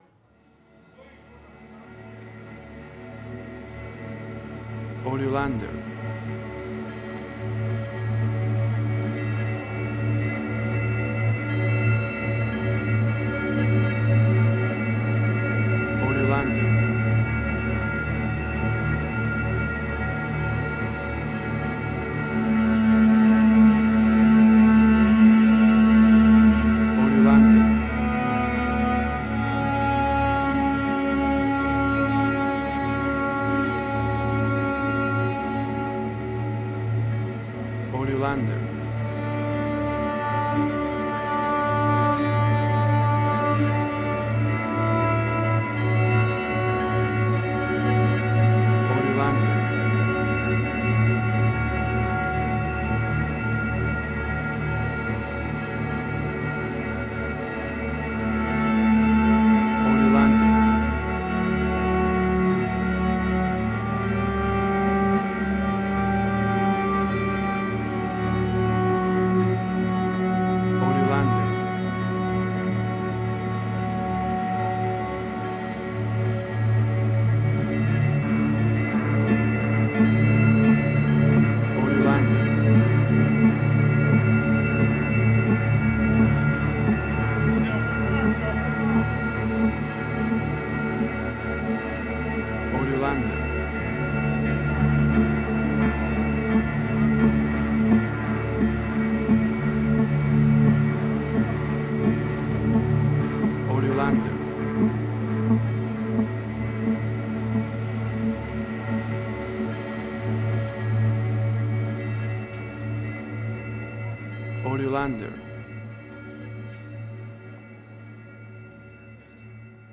Asian Ambient.